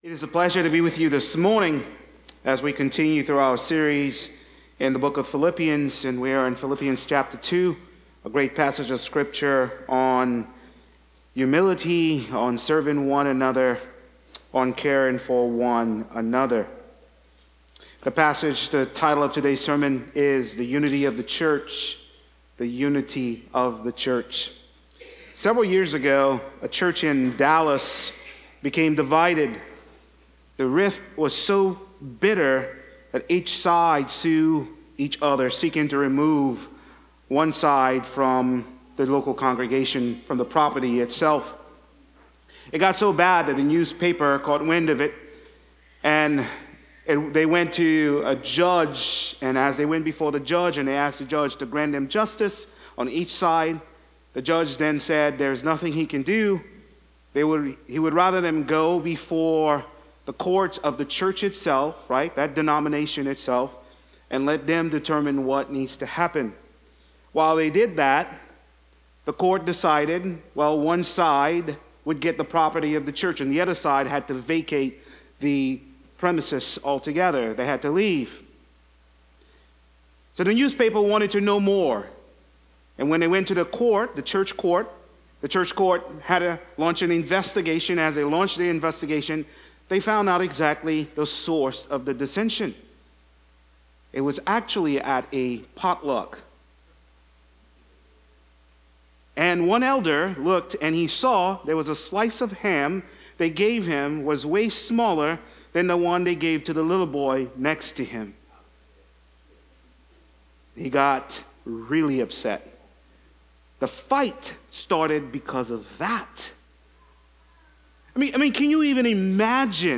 Sermons – FBC Thibodaux